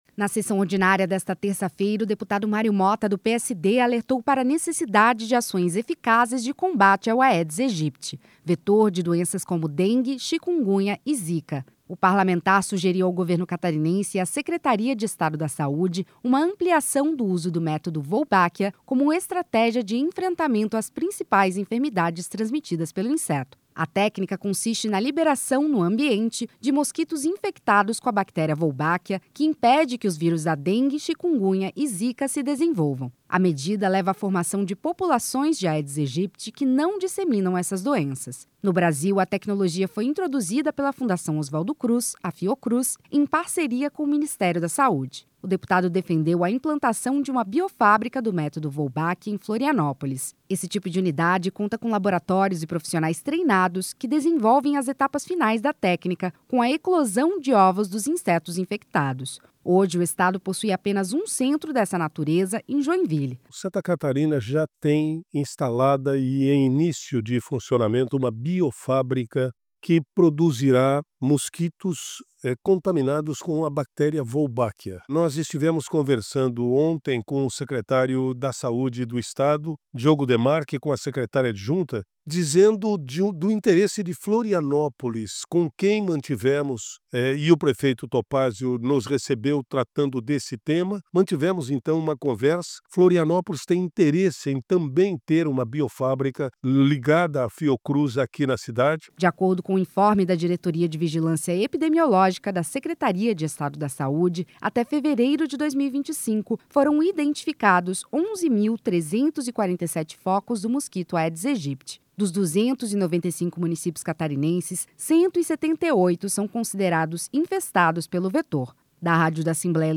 Entrevista com:
- deputado Mário Motta (PSD).